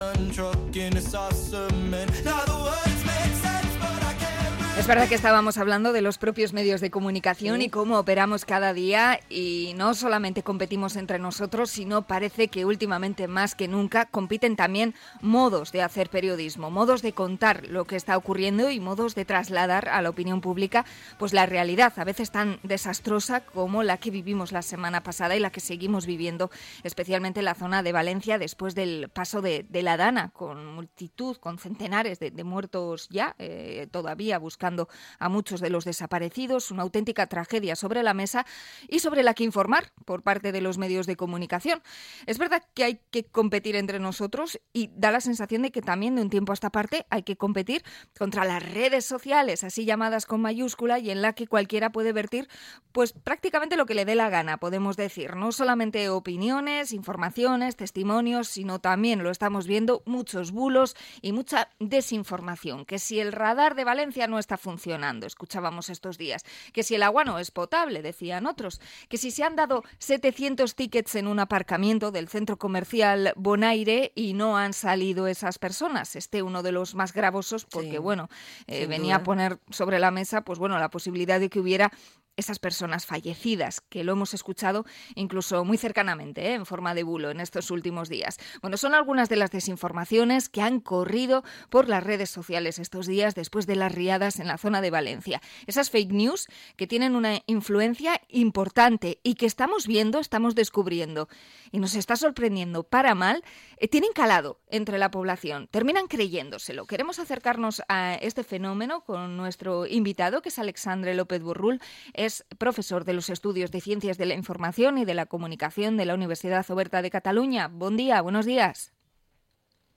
Entrevista a experto en comunicación por los bulos de la DANA